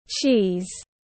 Pho mát tiếng anh gọi là cheese, phiên âm tiếng anh đọc là /tʃiːz/
Cheese /tʃiːz/